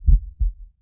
heartbeat1.wav